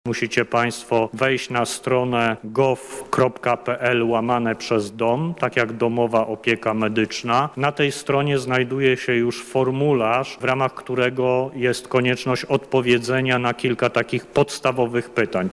O tym jak wygląda proces rejestracji na testy mówi minister zdrowia Adam Niedzielski: